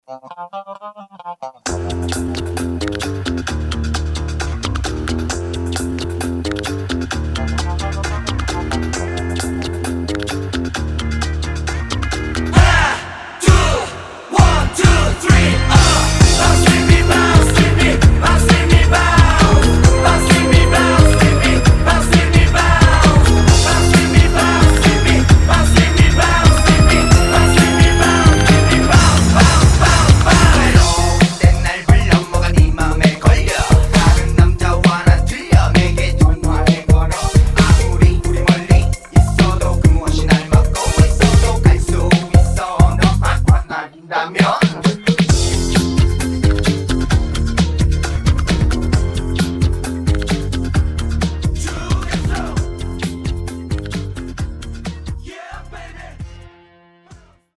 음정 원키 3:45
장르 가요 구분 Voice MR